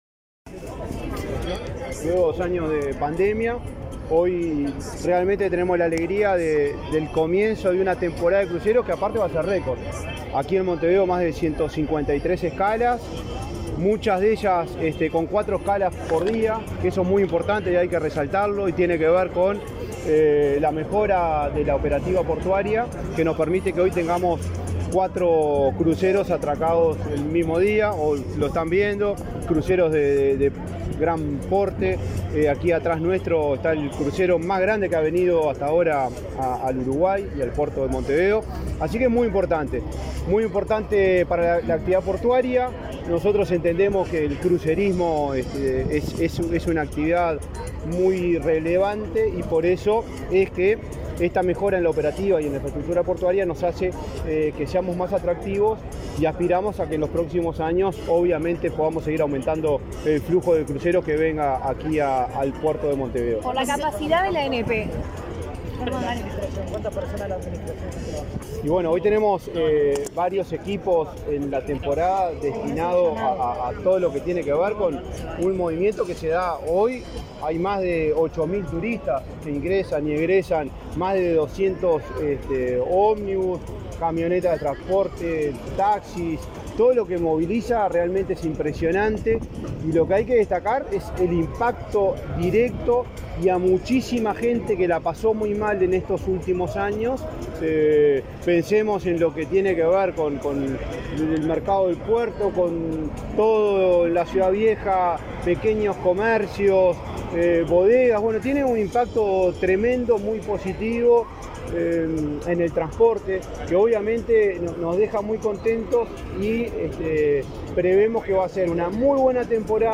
El presidente de la ANP, Juan Curbelo, dialogó con la prensa luego de participar del lanzamiento de la temporada de Cruceros en el Puerto de